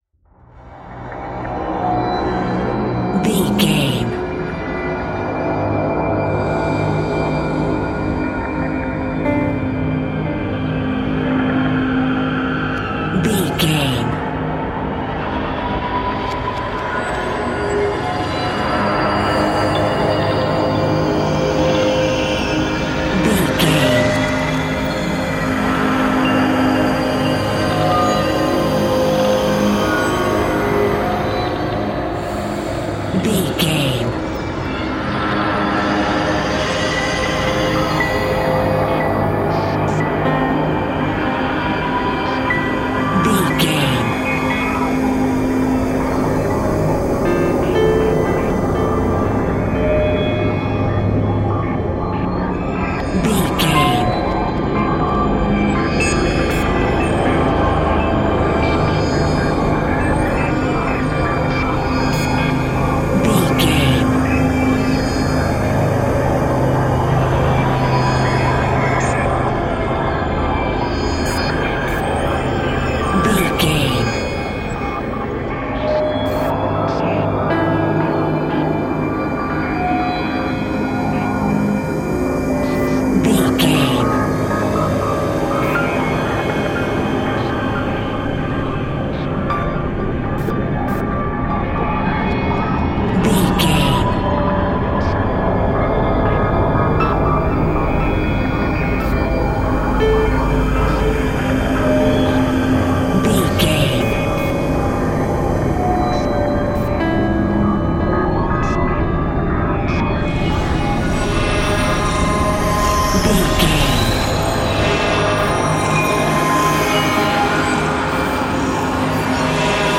Thriller
Atonal
ominous
dark
suspense
haunting
eerie
synthesizer
piano
ambience
pads